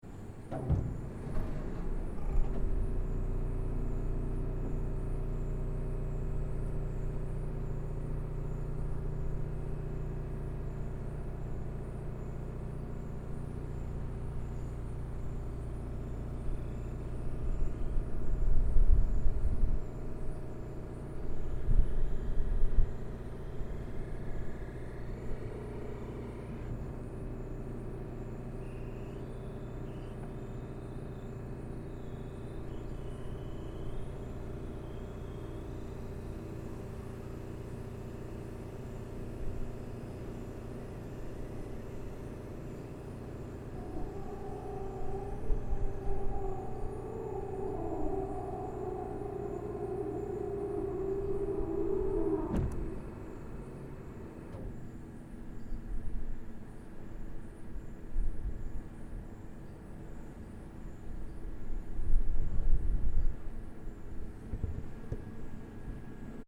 Soundscape: Paranal Auxiliary Telescopes (ATs) dome opening
Like a very slow-motion Pacman pointing to the sky, the Auxiliary Telescopes (ATs) opens its round enclosure after a day test previous to a night of observations. Although the opening sound of this dome is quite discreet, this audio also captures sounds of the AT´s electronic cabinets, liquid cooling system and air-conditioning units.
Soundscape Stereo (mp3)
ss-paranal-at-dome-opening_stereo.mp3